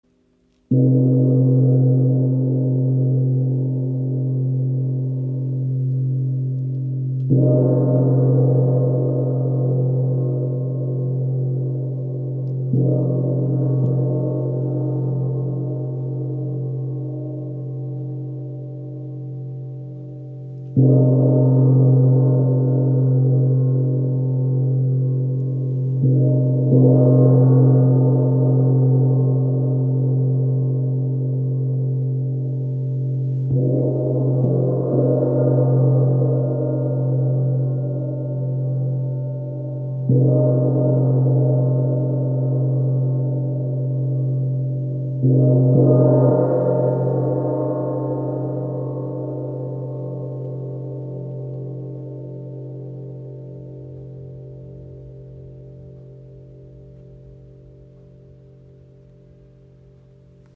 Chinesischer Wind Gong – Einzigartiger Klang für Meditation, Heilung und Konzerte • Raven Spirit
Klangbeispiel
Ein sanfter Schlag – und der Gong erwacht. Sein Klang breitet sich aus, schwebend wie der Wind, tief, klar und durchdringend. Der Wind Gong aus hauchdünner Bronze hat eine besondere Resonanz, die sanfte, fast mystische Töne erzeugt, aber auch kraftvolle, tiefgehende Vibrationen.
Weiterlesen Klangbeispiel Wind Gong | ø 50 cm | C3